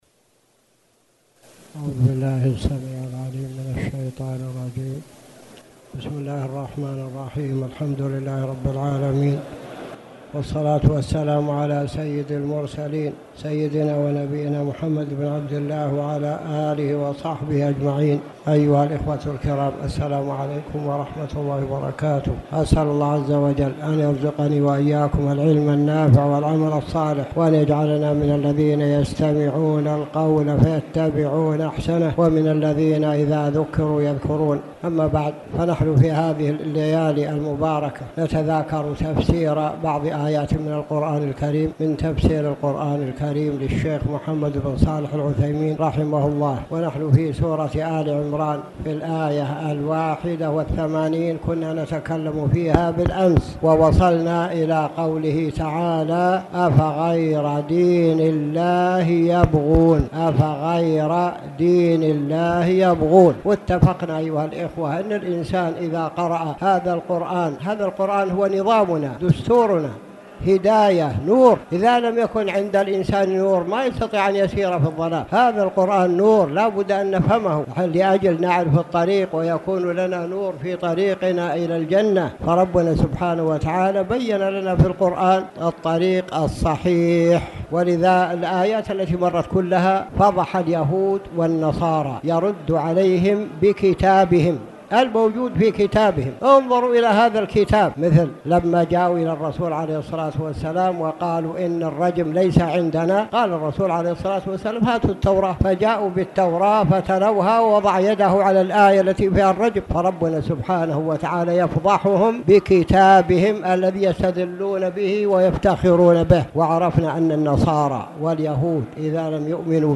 تاريخ النشر ١٩ رمضان ١٤٣٨ هـ المكان: المسجد الحرام الشيخ